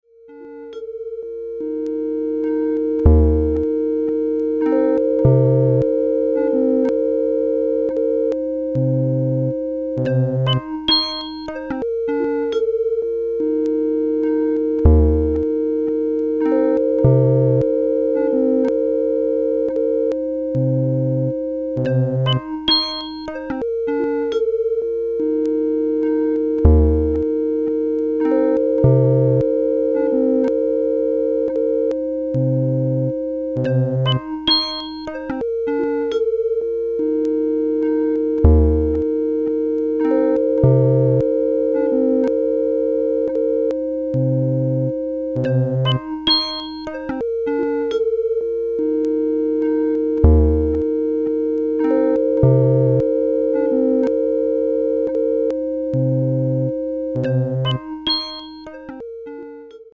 It has this "instant Eno" sound quality that's quite beautiful.